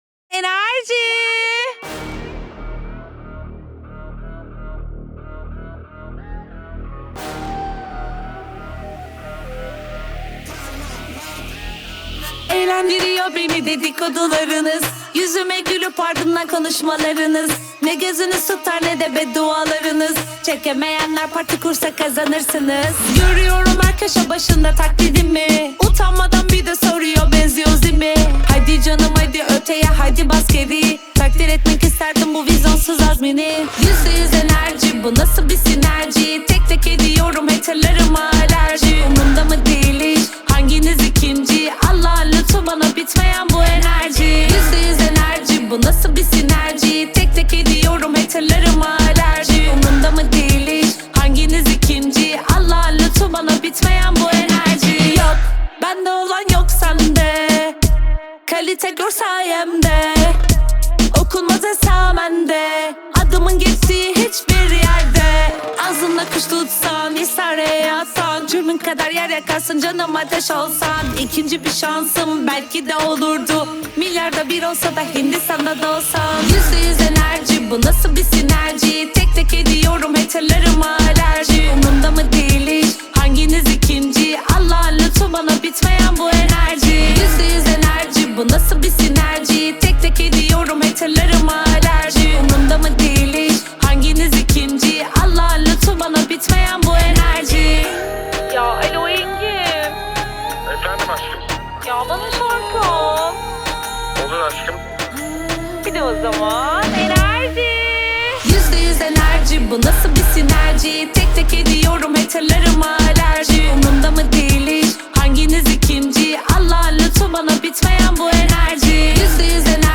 آهنگ ترکیه ای آهنگ شاد ترکیه ای آهنگ هیت ترکیه ای ریمیکس